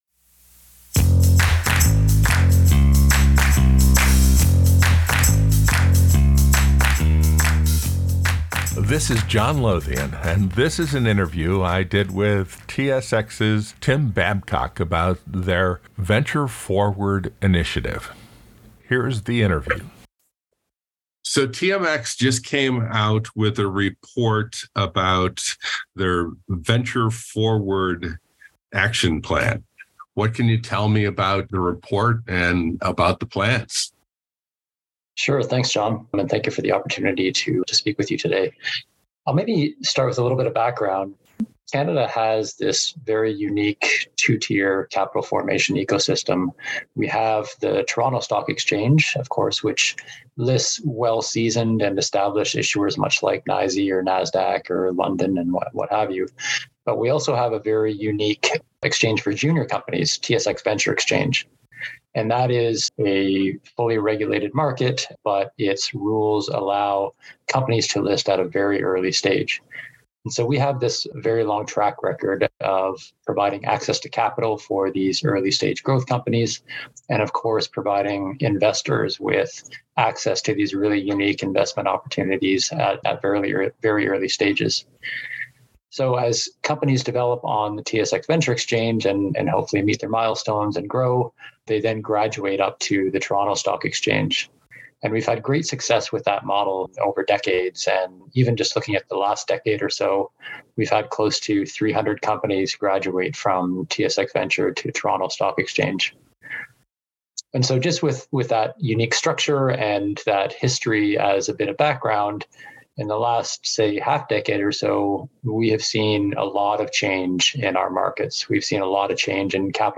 was recently interviewed by John Lothian News about TSX's Venture Forward initiative